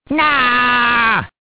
One of Waluigi's voice clips in Mario Kart DS